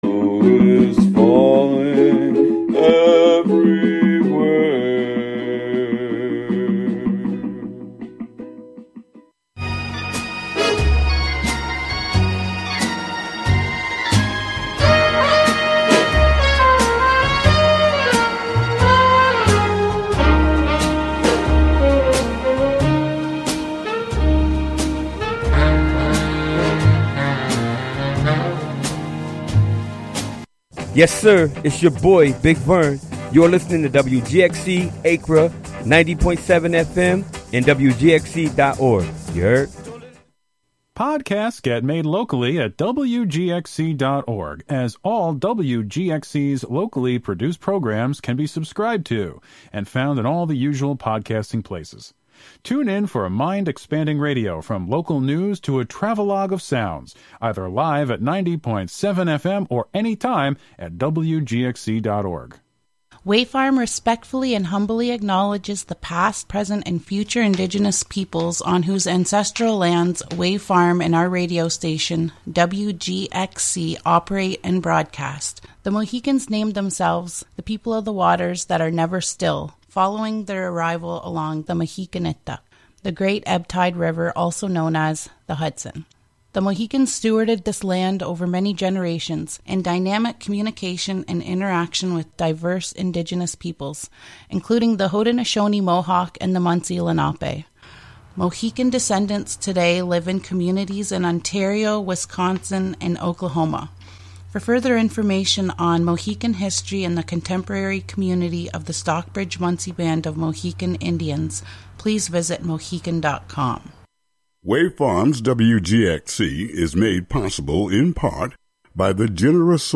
short stories and fables from around the world that are available in print will be the only topic here. i won’t offer commentary or biographies on-air. just the fictions read for the listener as best as i can read them.